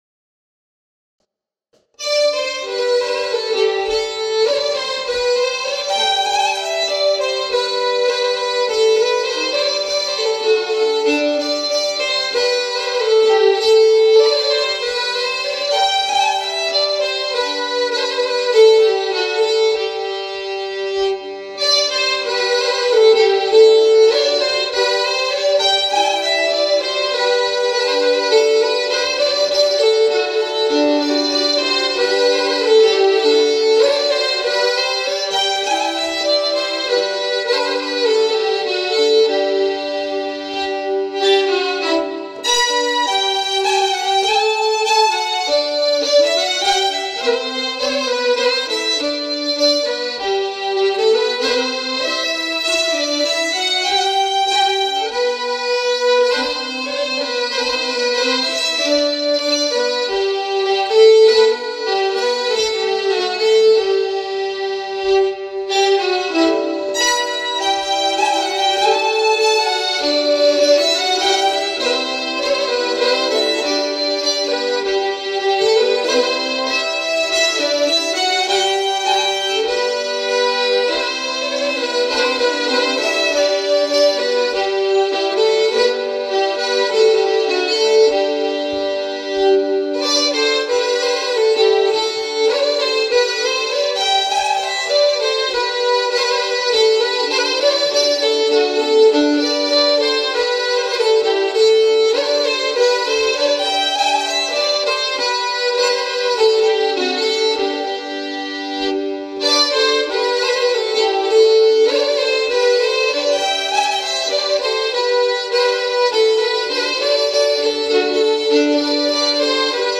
(Fiol)